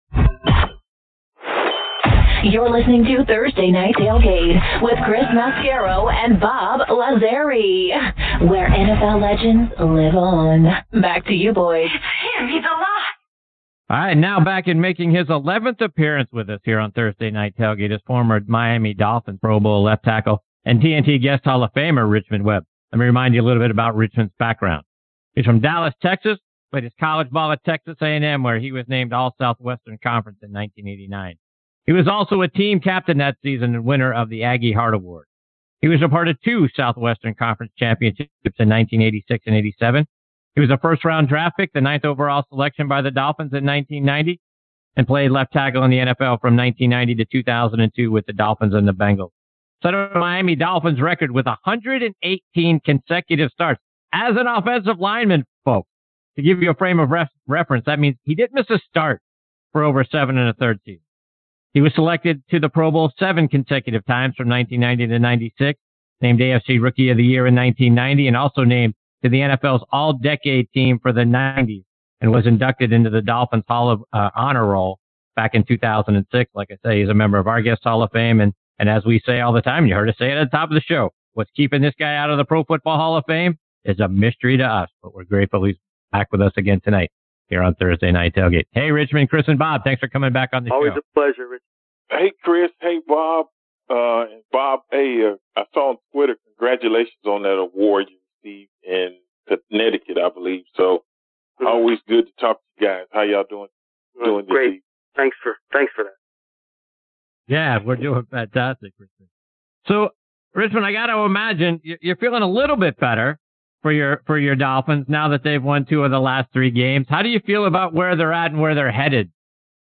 Former Texas A&M and Miami Dolphins Pro Bowl LT Richmond Webb shares his thoughts on both of his former teams on this segment of Thursday Night Tailgate.